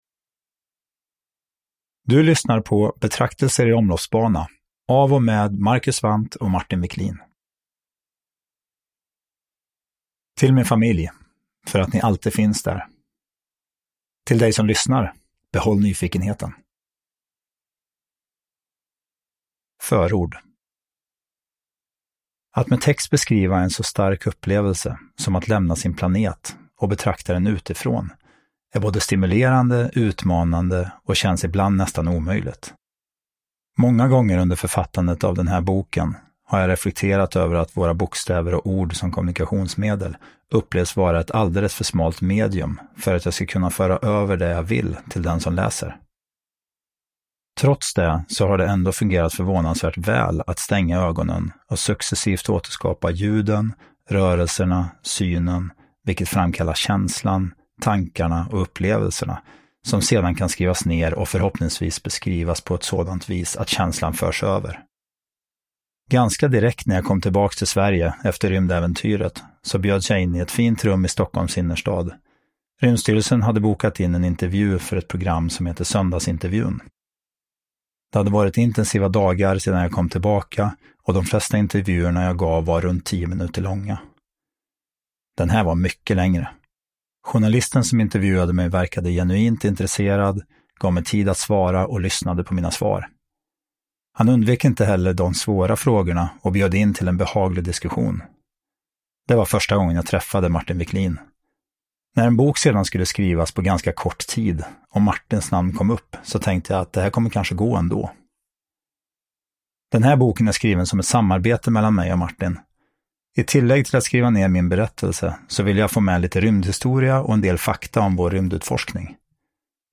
Betraktelser i omloppsbana – Ljudbok
Uppläsare: Marcus Wandt, Martin Wicklin